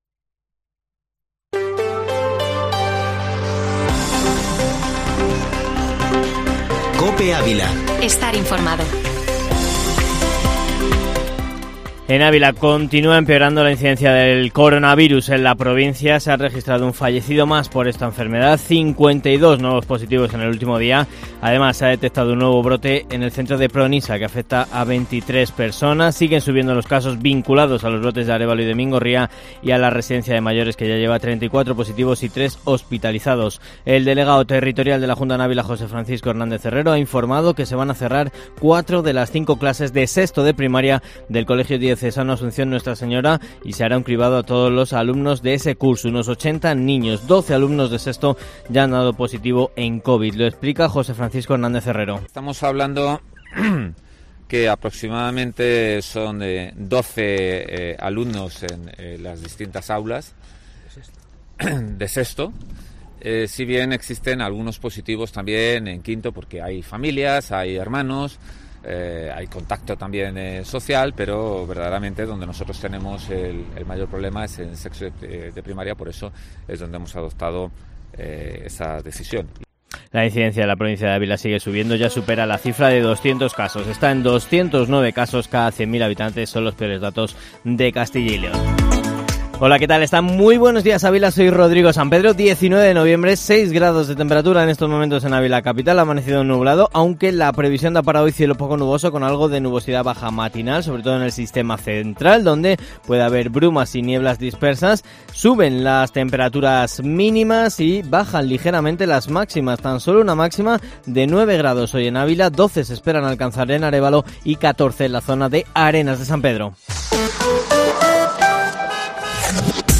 Informativo Matinal Herrera en COPE Ávila -19-nov